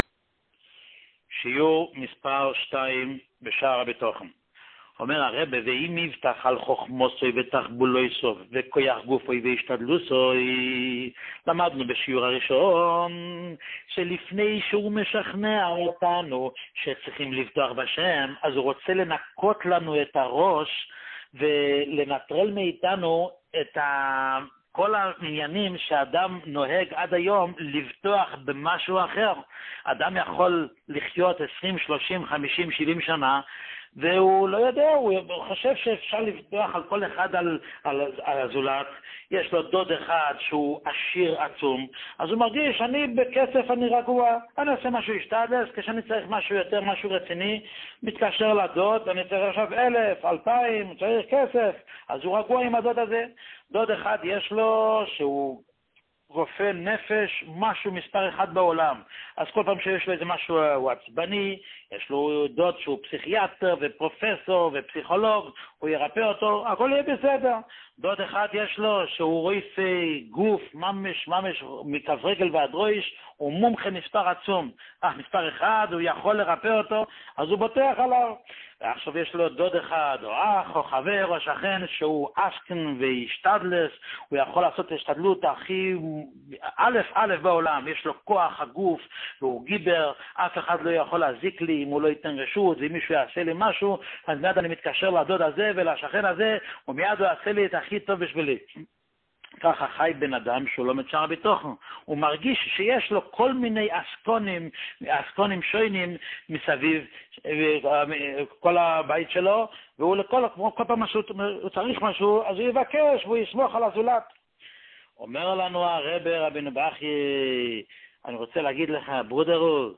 שיעורים מיוחדים